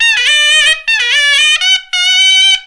SHENNAI2  -L.wav